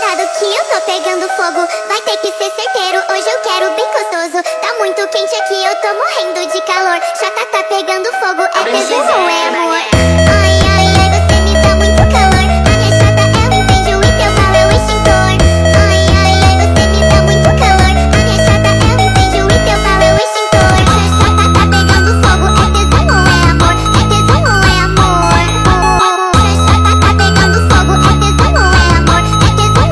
Жанр: Фанк